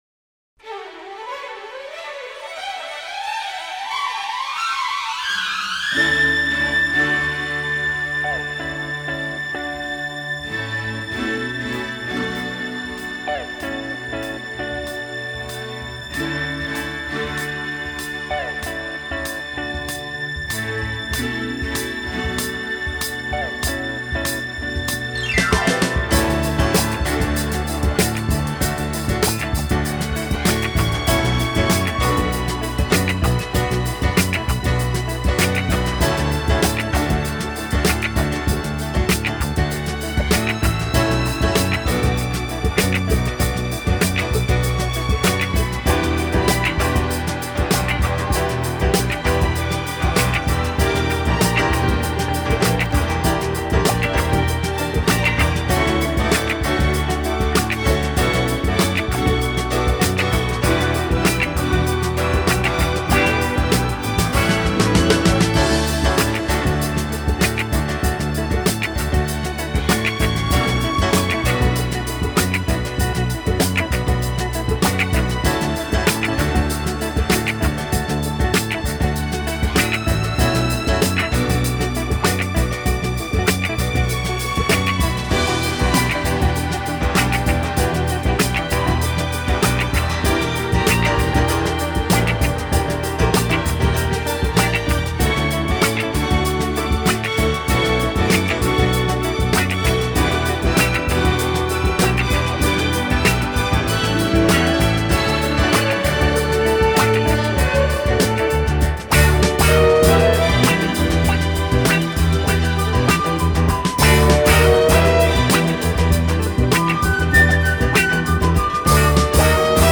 глубокий баритон и откровенно соблазнительная лирика.